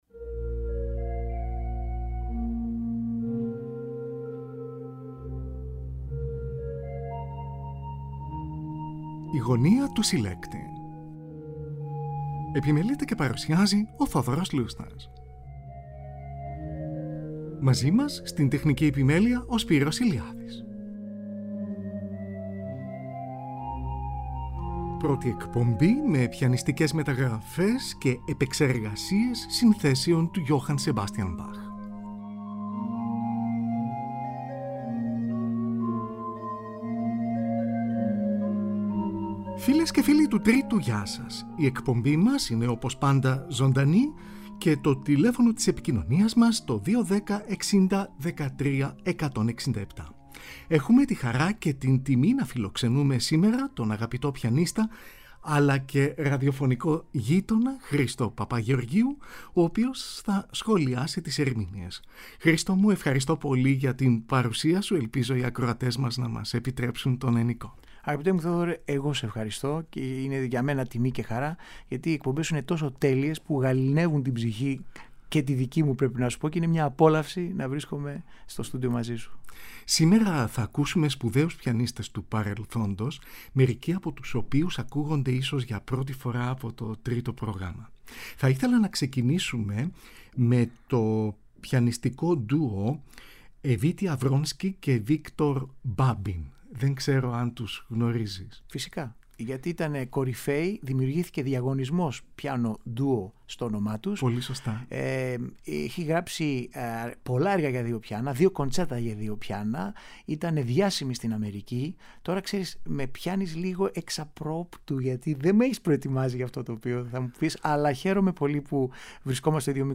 ΠΡΩΤΗ ΕΚΠΟΜΠΗ ΜΕ ΠΙΑΝΙΣΤΙΚΕΣ ΜΕΤΑΓΡΑΦΕΣ ΚΑΙ ΕΠΕΞΕΡΓΑΣΙΕΣ ΣΥΝΘΕΣΩΝ ΤΟΥ JOHANN SEBASTIAN BACH